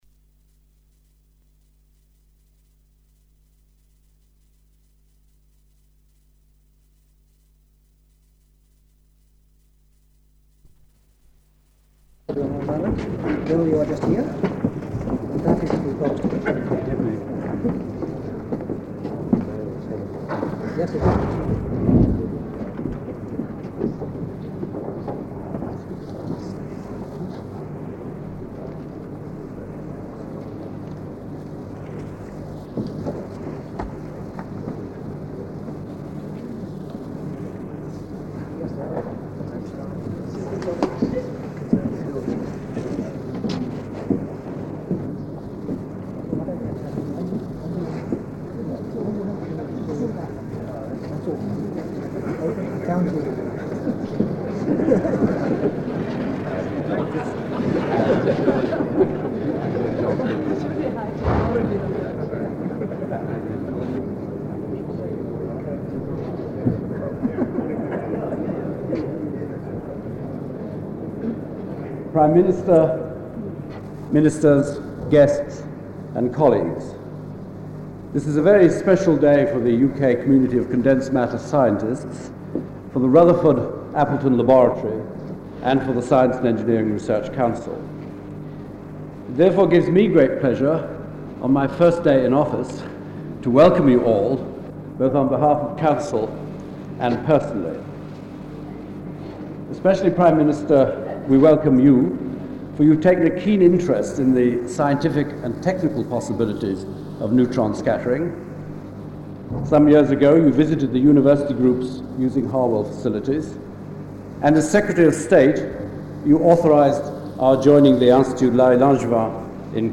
isis-opening-ceremony.mp3